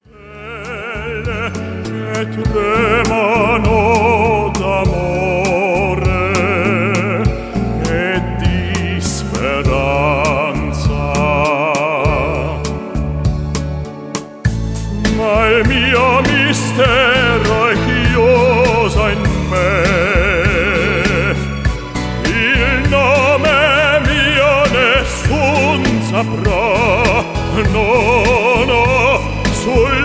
Famous tenor aria performed by german opera-bass
Pop-Version.